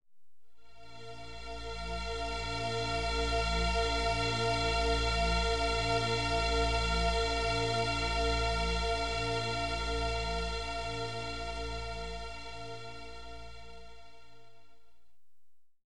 B mit 458 Hz und 432 Hz Kammerton,
mit Schwebung im Theta Bereich,
inklusive Quinte und tieferen B Oktaven
B458Hz_Lebenskraft_opti.wav